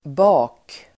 Uttal: [ba:k]